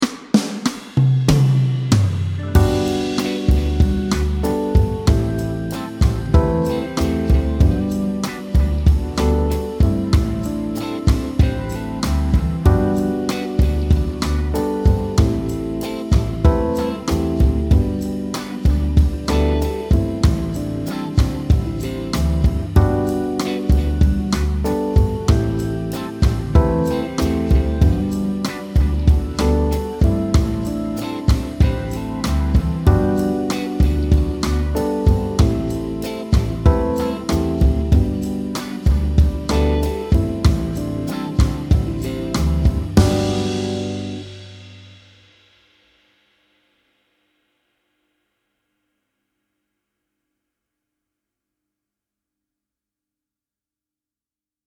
2 akkorder:
Første akkord er tonika.
C instrument (demo)
Spil solo eller skalatoner over musikken der indeholder to akkorder.
Akkorder-del-1-kap-4-G-dur-2.trin-C.mp3